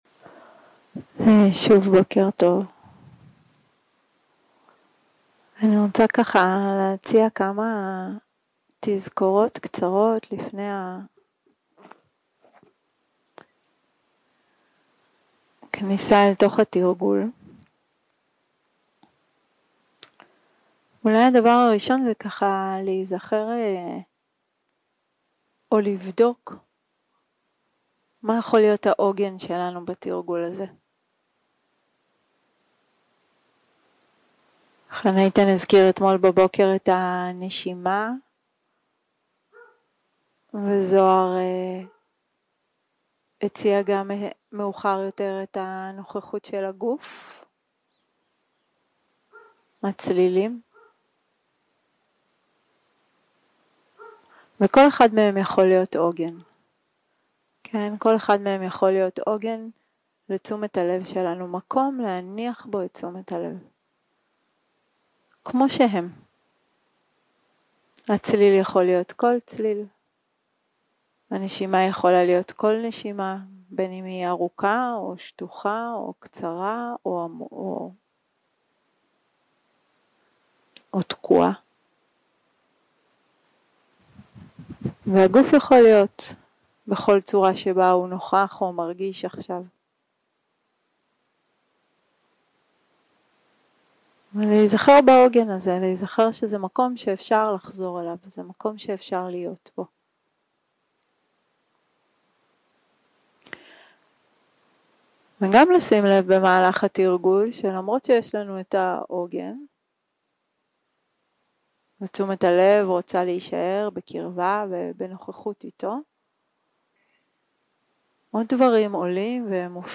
יום 3 – הקלטה 5 – בוקר – הנחיות למדיטציה – התרווחות במקום דרישה 0:00 0:00 סוג ההקלטה: Dharma type: Guided meditation שפת ההקלטה: Dharma talk language: Hebrew